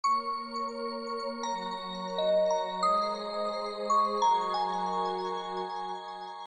Kategori Alarm